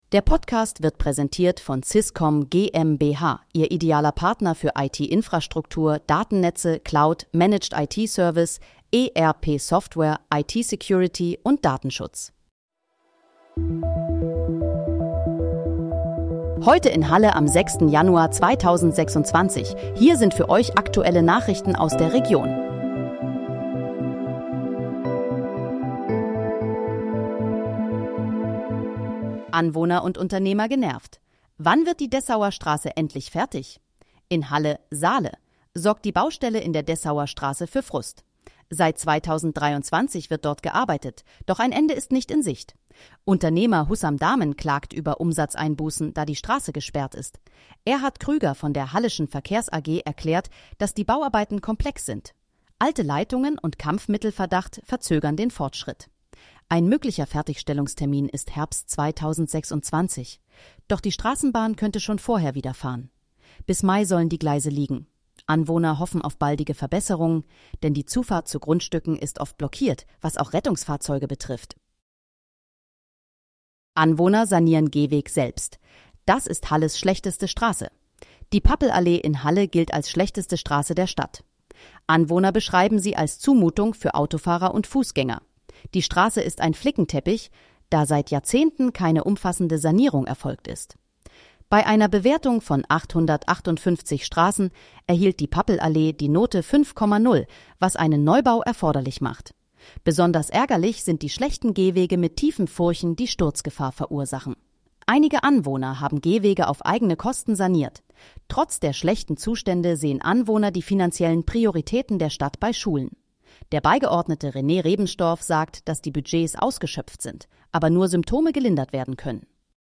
Heute in, Halle: Aktuelle Nachrichten vom 06.01.2026, erstellt mit KI-Unterstützung
Nachrichten